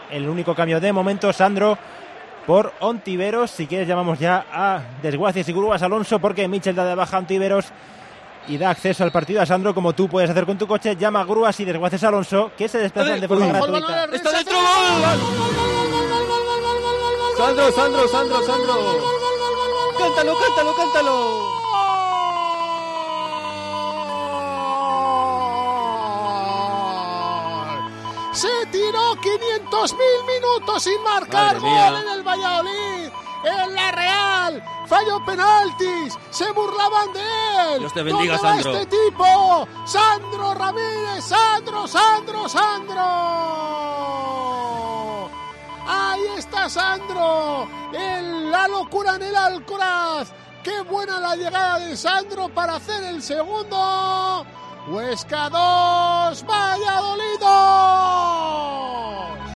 Narración Gol de Sandro / Huesca 2-2 Real Valladolid